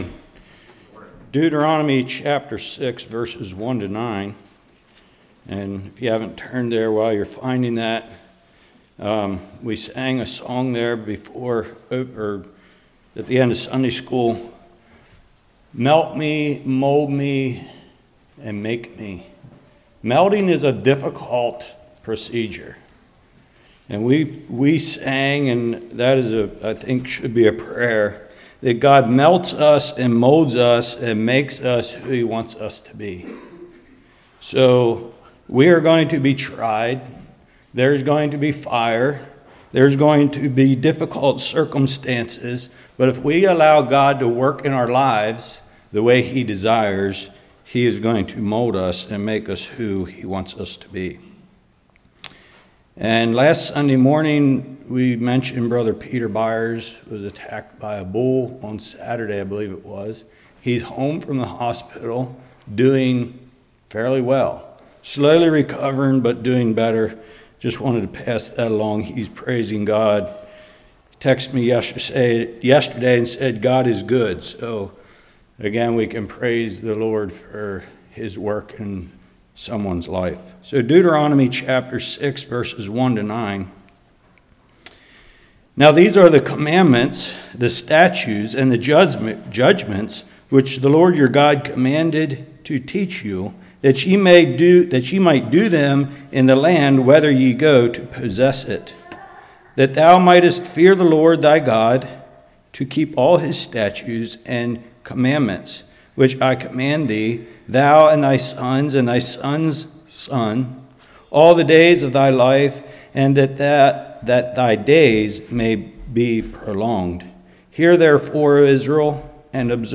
Deuteronomy 6:1-9 Service Type: Morning Parents need to make the final decision on how much discipline to give.